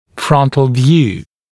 [‘frʌnt(ə)l vjuː][‘франт(э)л вйу:]вид анфас, вид в передней проекции